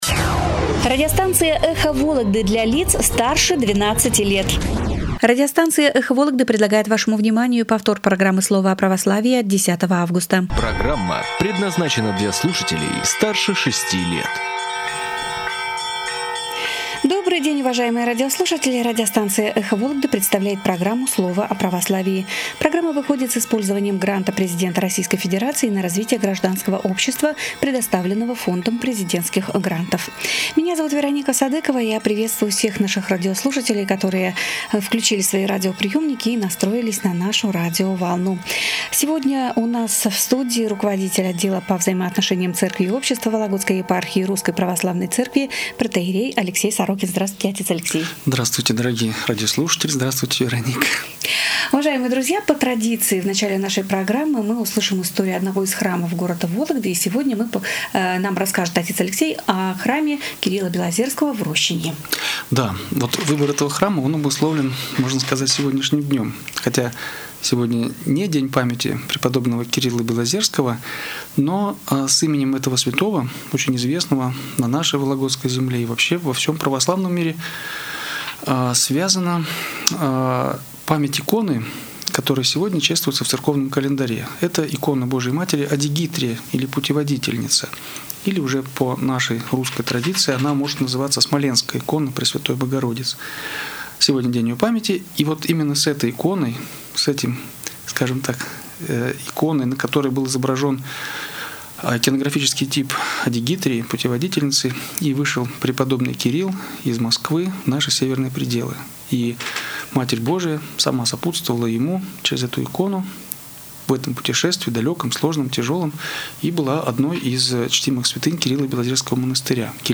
На радио "Эхо Вологды " в прямом эфире прозвучала очередная программа "Слово о Православии".